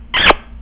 kiss.au